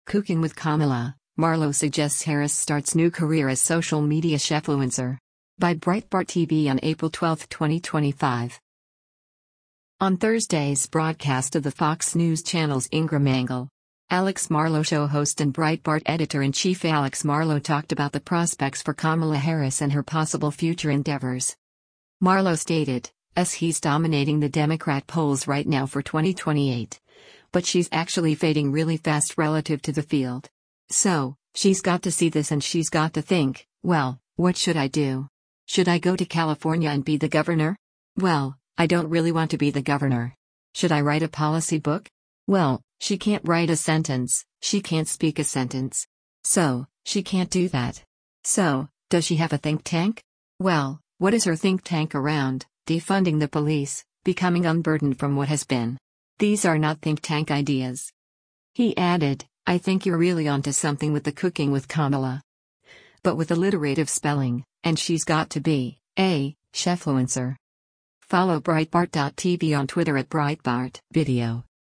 On Thursday’s broadcast of the Fox News Channel’s “Ingraham Angle,” “Alex Marlow Show” host and Breitbart Editor-in-Chief Alex Marlow talked about the prospects for Kamala Harris and her possible future endeavors.